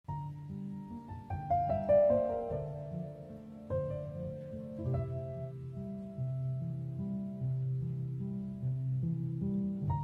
water-flow-audio.mp3